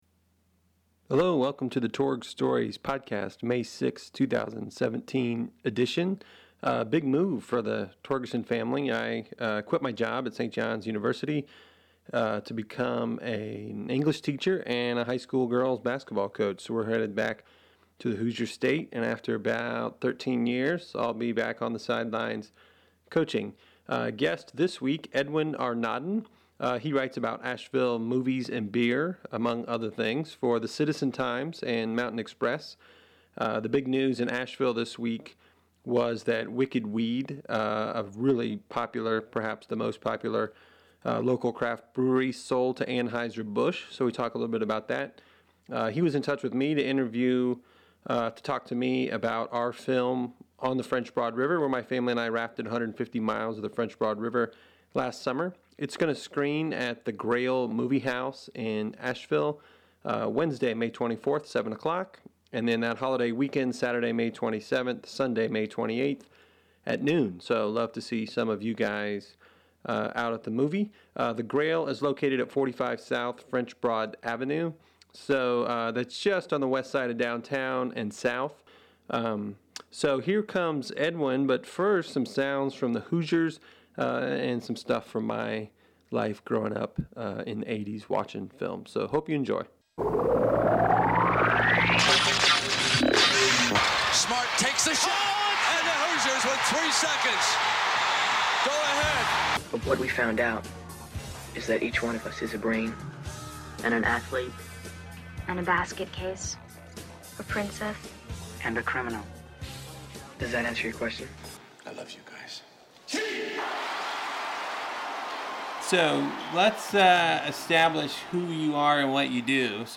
and so I asked him to join me for a conversation about Asheville and freelance writing. We also talked about what was then the breaking news that the popular local craft brewery Wicked Weed had been sold to Anheuser-Busch.